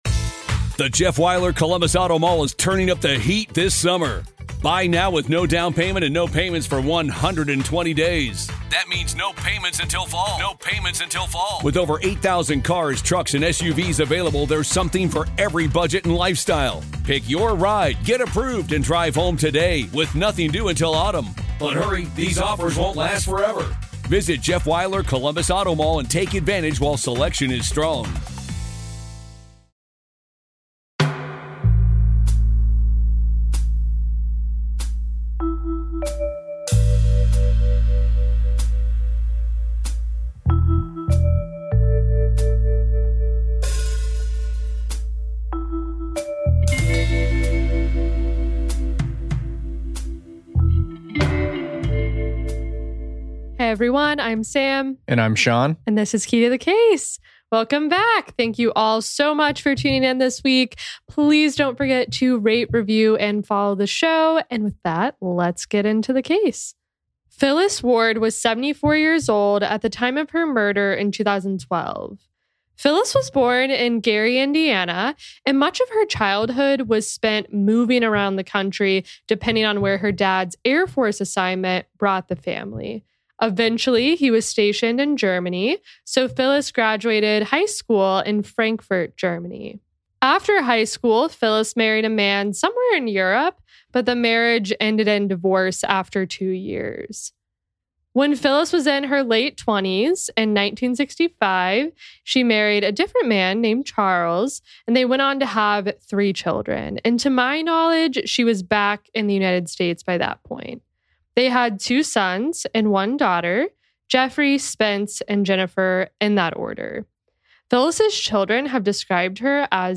There were some unresolvable audio issues in this episode, mostly in the first five minutes but a few throughout as well.